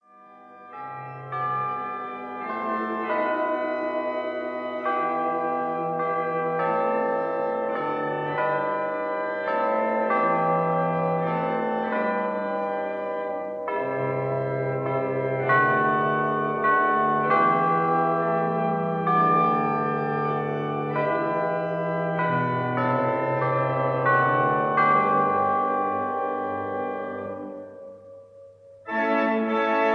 chimes